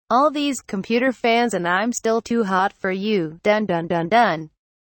computer fans